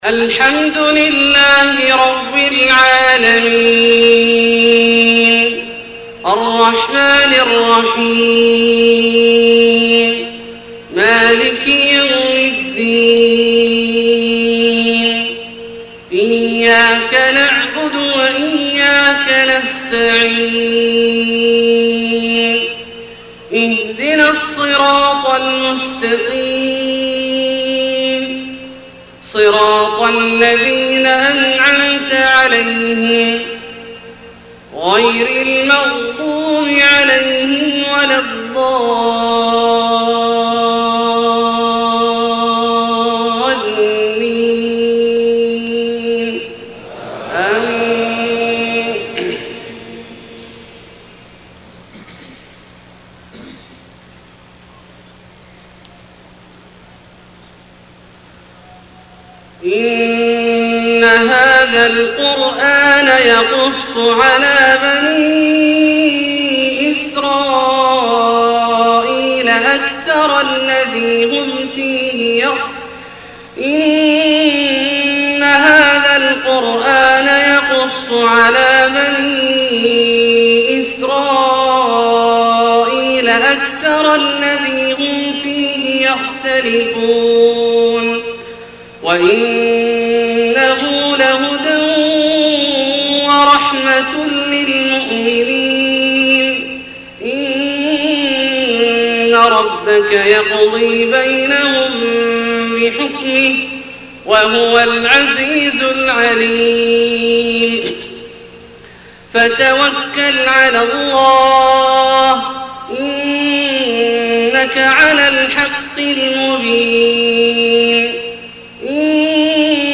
صلاة العشاء 7-3-1431 من اواخر سورة النمل {76-93} > ١٤٣١ هـ > الفروض - تلاوات عبدالله الجهني